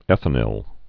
(ĕthə-nĭl)